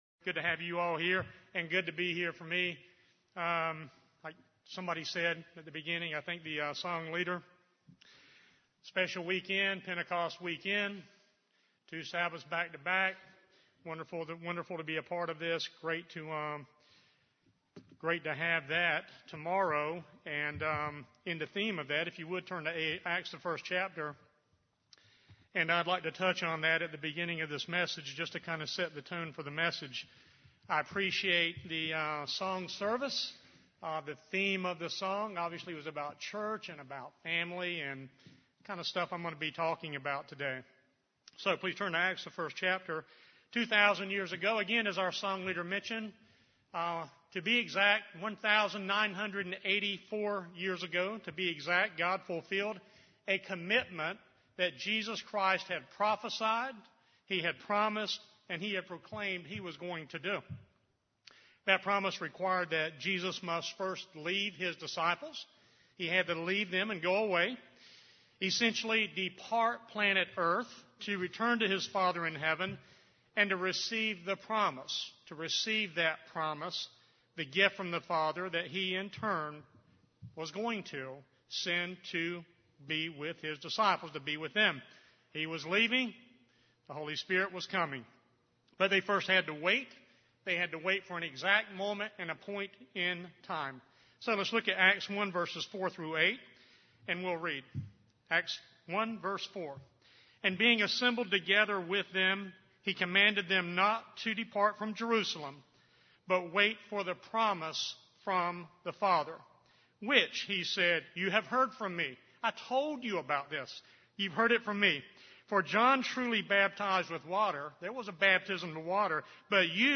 The outpouring of GOD’s Holy Spirit on Pentecost AD 31 fundamentally changed everything for CHRISTs Disciples then, as well as those of us who are HIS disciples today who also have received the Promise of GOD, the gift of GOD’s Holy Spirit. This sermon reviews what I consider the most significant aspect of a person’s receiving the gift of GOD’s Holy Spirit and that is the special Family relationship it offers us with GOD, that through the indwelling Holy Spirit we are in fact now and are to be born fully the very ‘Children of the FATHER’.